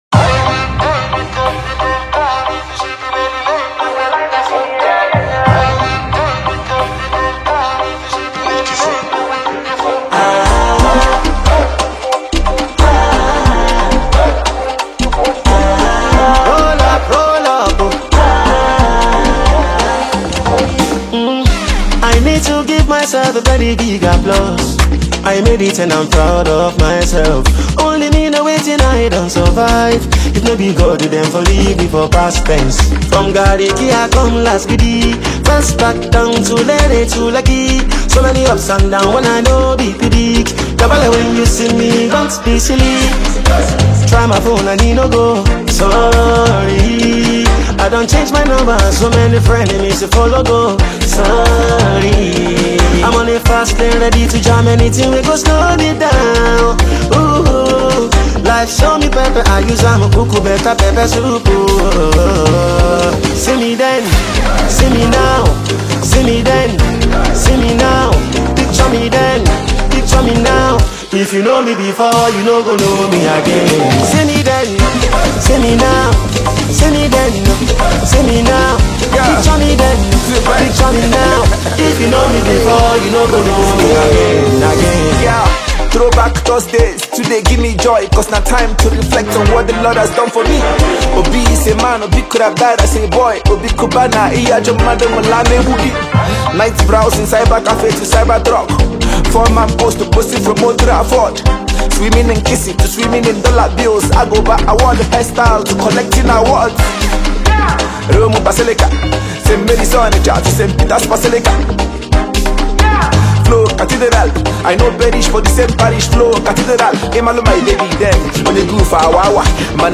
You will never forget the amazing melody of this song.